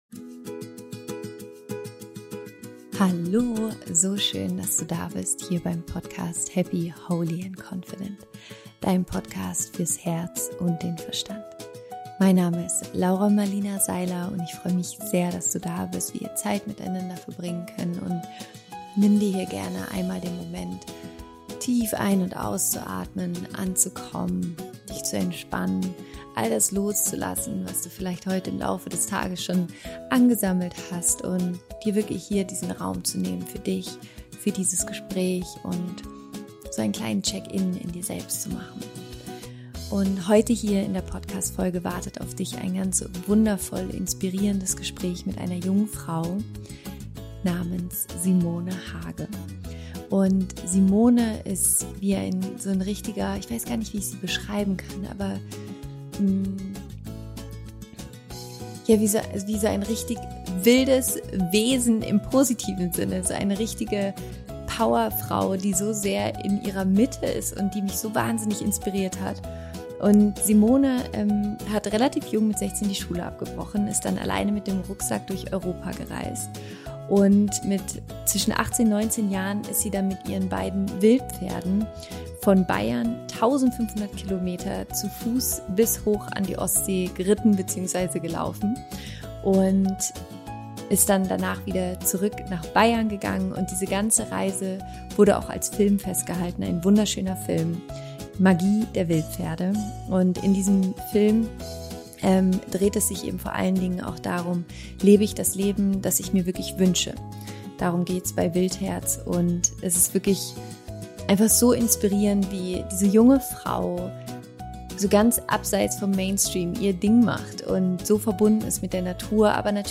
Viel Spaß mit diesem berührenden Interview!